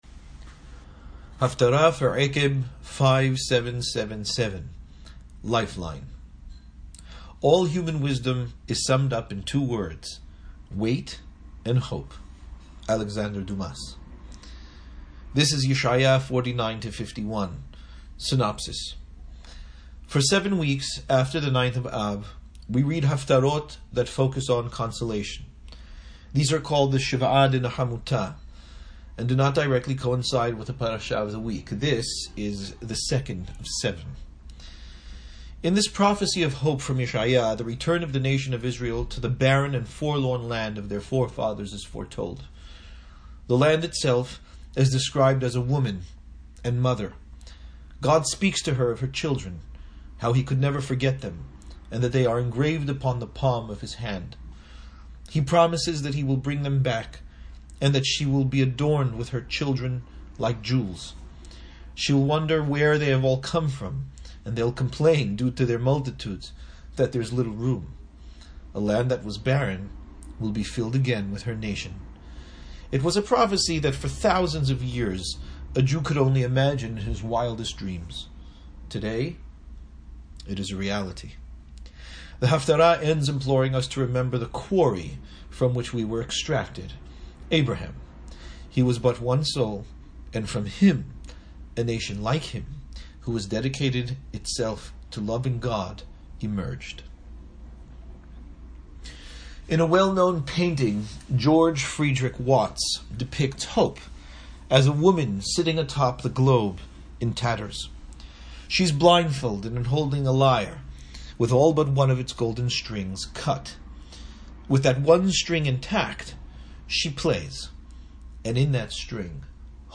Haftara for Ekeb 5777 : Lifeline ‘All human wisdom is summed up in two words; wait and hope.